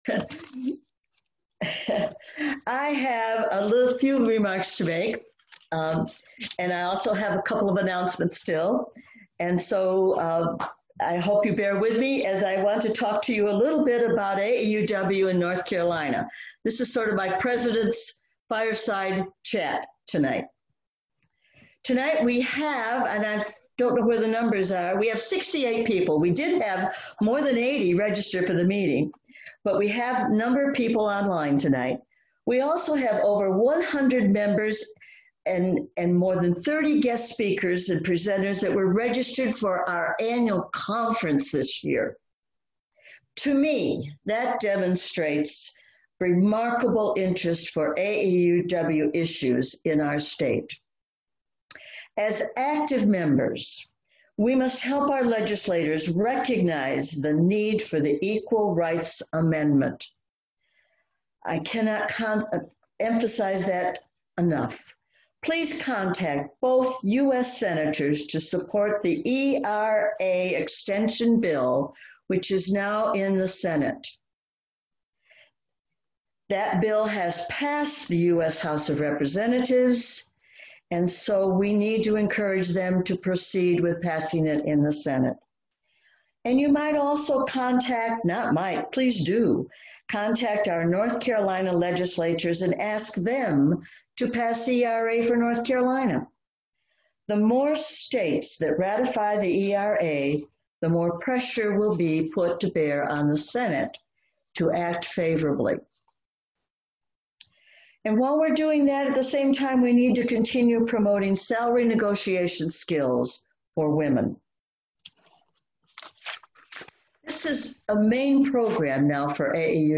Virtual Meeting Included 16 of 18 Branches
Our first virtual Annual Meeting on May 13, 2020 attracted 65 members from 16 branches!